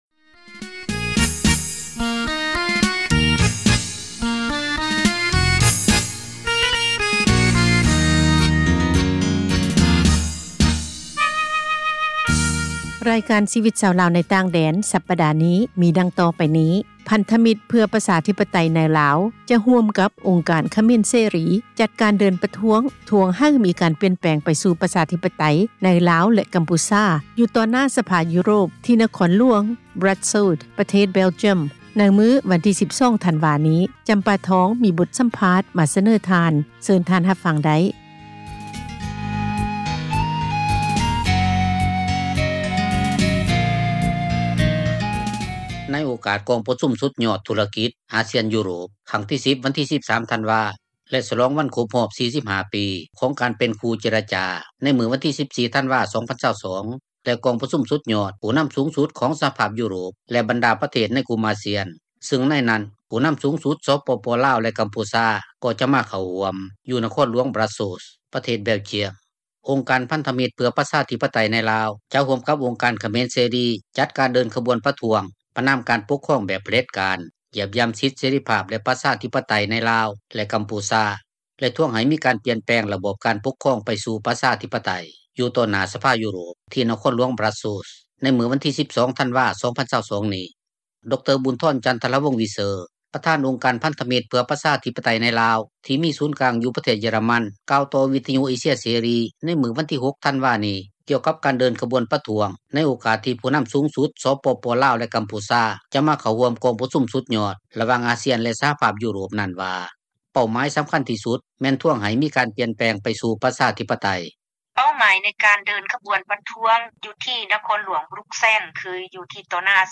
ມີບົດສັມພາດ